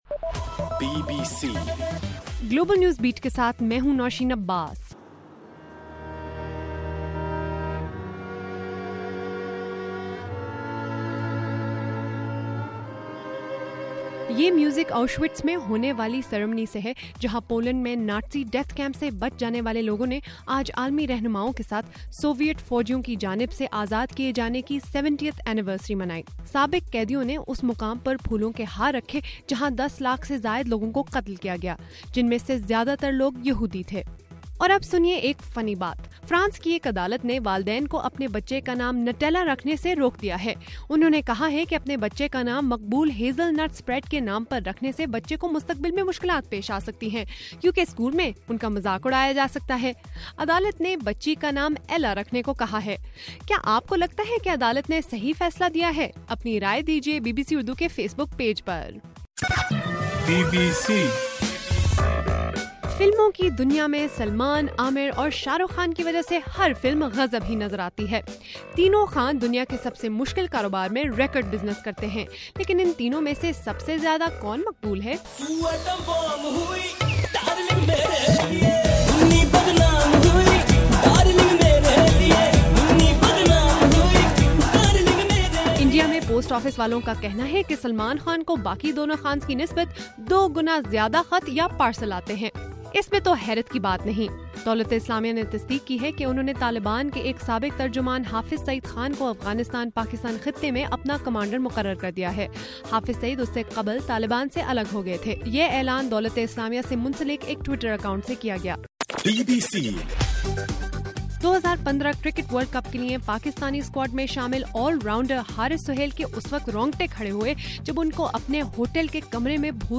جنوری 27: رات 11 بجے کا گلوبل نیوز بیٹ بُلیٹن